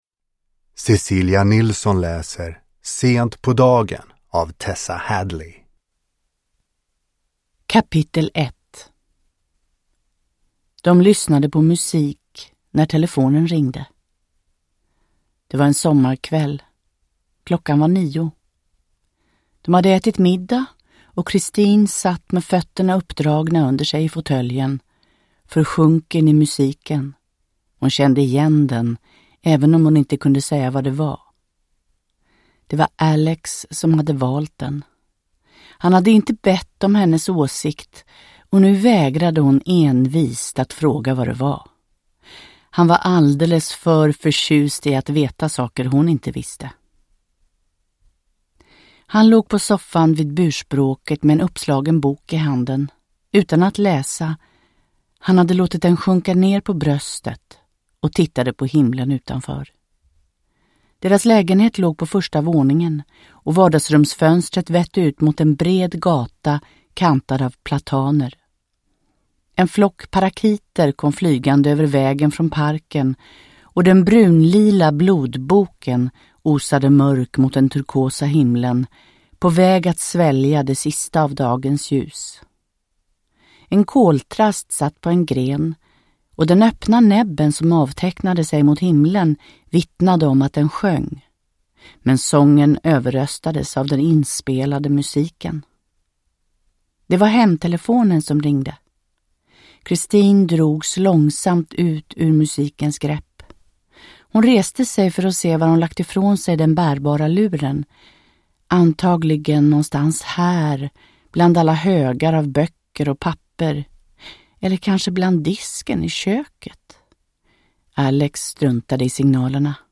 Sent på dagen – Ljudbok – Laddas ner